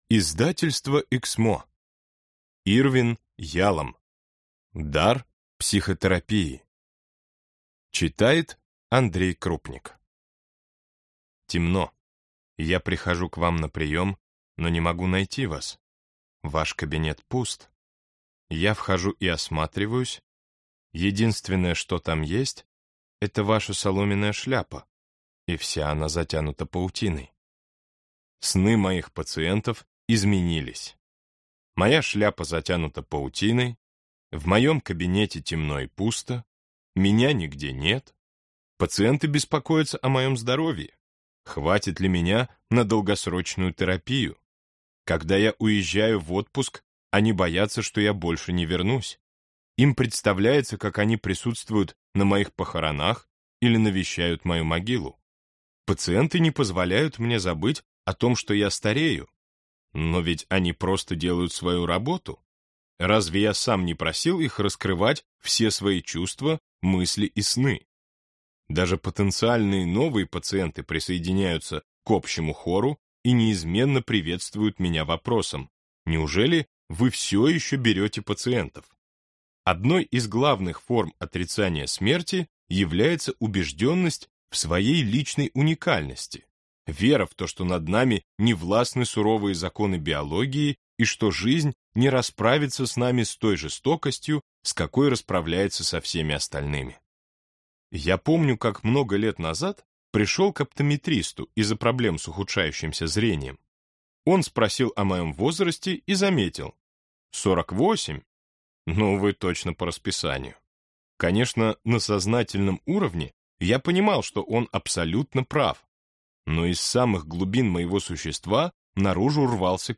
Аудиокнига Дар психотерапии | Библиотека аудиокниг